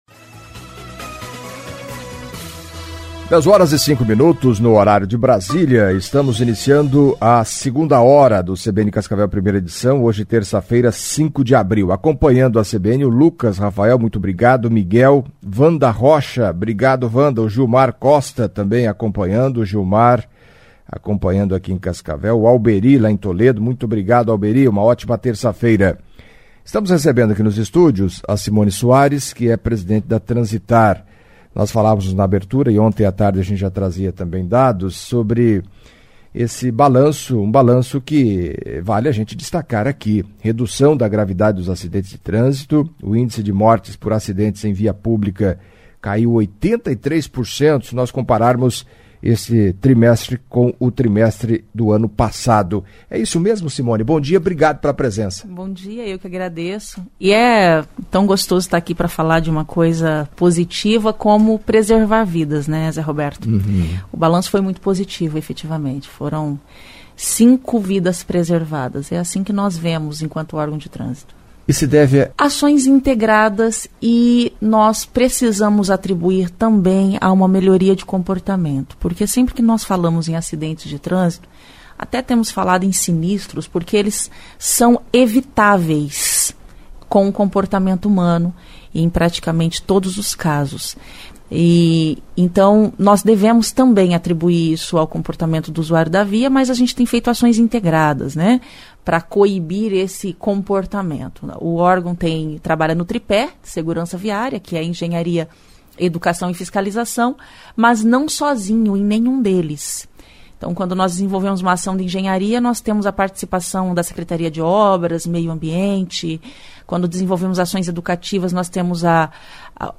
Em entrevista à CBN Cascavel nesta terça-feira (05) Simoni Soares, presidente da Transitar, falou, entre outros assuntos, da redução do número de acidentes com mortes no primeiro trimestre de 2022, em comparação com os três primeiros meses de 2021.